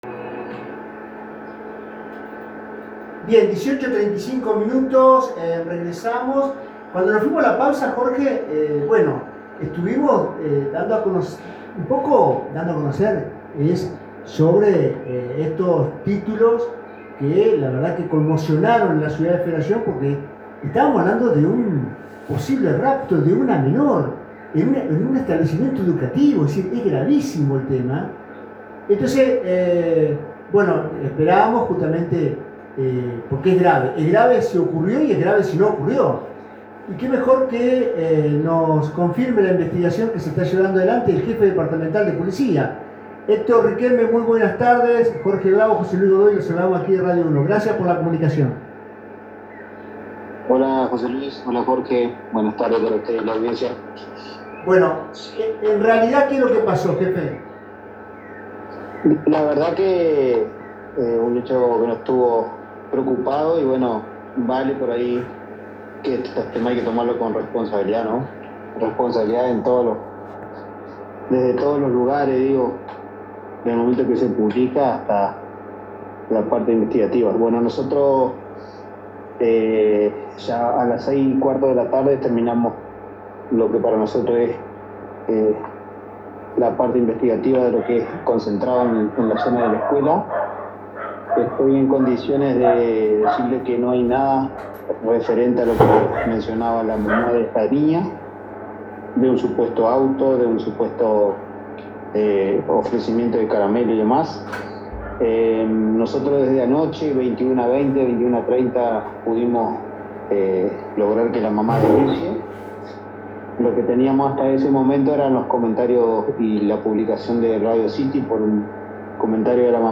El funcionario brindó detalles en el programa Entre Líneas de Radio UNO Federación, donde explicó que la investigación oficial permitió descartar por completo la versión que generó preocupación en la comunidad.